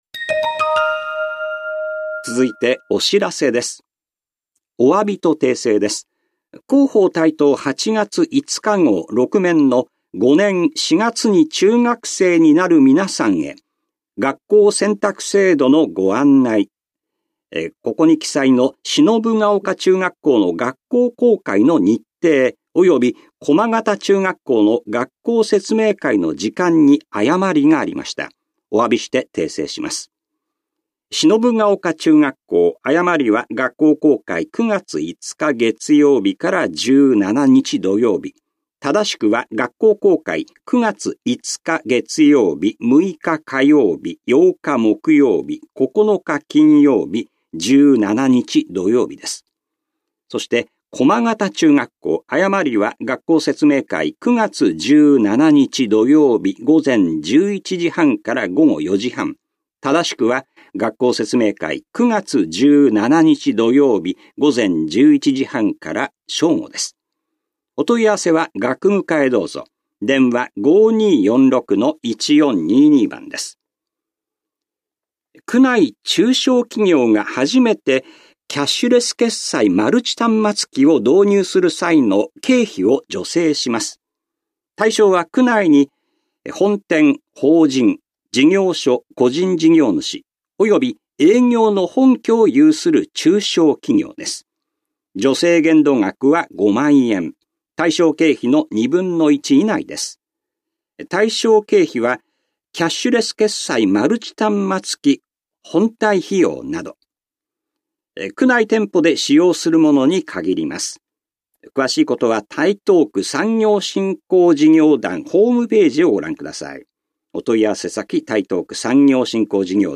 広報「たいとう」令和4年8月20日号の音声読み上げデータです。